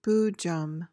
PRONUNCIATION: (BOO-juhm) MEANING: noun: An imaginary or elusive goal whose pursuit may lead to ruin.